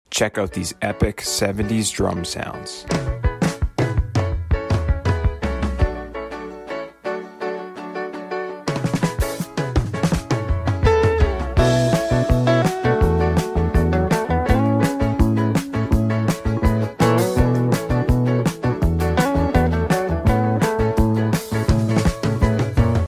Here are some 70’s drum sound effects free download
Here are some 70’s drum sounds. This kit is perfect for this style and finally dialed in the tones perfectly!